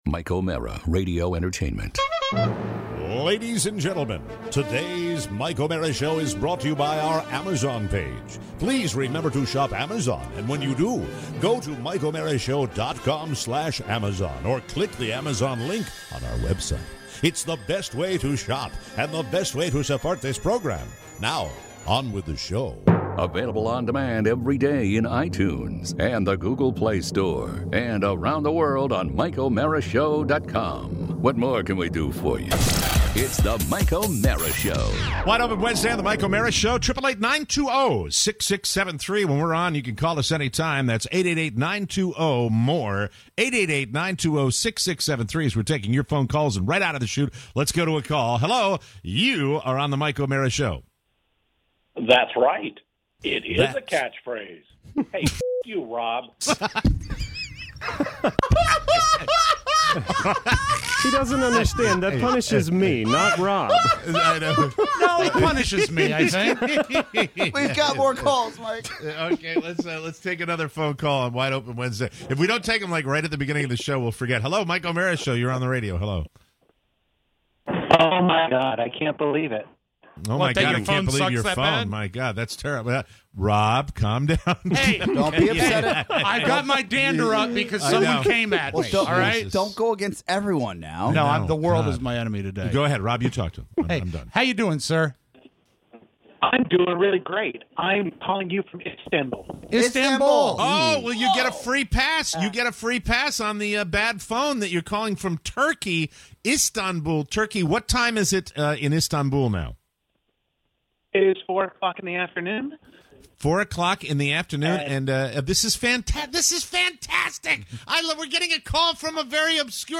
Your calls!